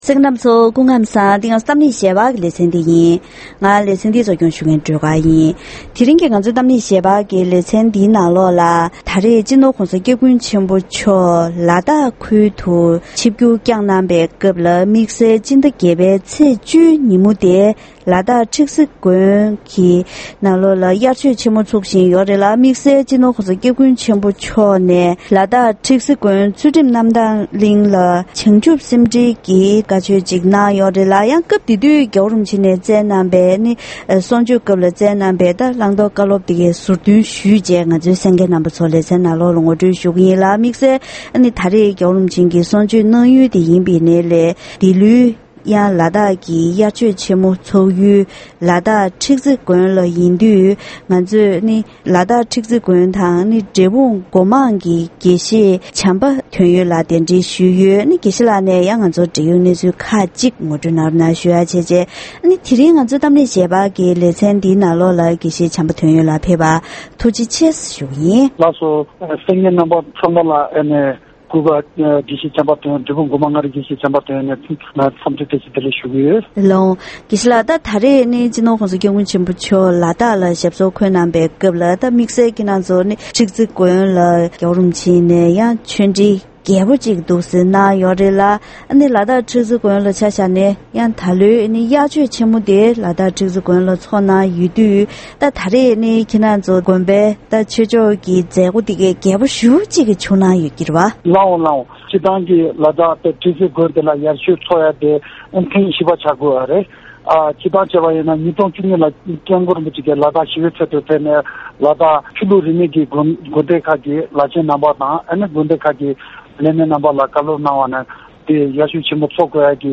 ༄༅། །དེ་རིང་གི་གཏམ་གླེང་ཞལ་པར་ལེ་ཚན་ནང་སྤྱི་ནོར་༧གོང་ས་༧སྐྱབས་མགོན་ཆེན་པོ་མཆོག་ནས་ལ་དྭགས་སུ་བྱང་ཆུབ་སེམས་འགྲེལ་དང་བྱང་ཆུབ་ལམ་སྒྲོན་གྱི་གསུང་ཆོས་གནང་སྐབས་དད་ལྡན་མང་ཚོགས་ཚོར་ནང་ཆོས་སློབ་སྦྱོང་བྱས་ཏེ་རང་གི་སེམས་རྒྱུད་ལ་འགྱུར་བ་འགྲོ་ཐུབ་པ་དགོས་སྐོར་སོགས་བཀའ་སློབ་གནང་བའི་ཞིབ་ཕྲའི་གནས་ཚུལ་ཞུས་པ་ཞིག་གསན་རོགས་གནང་།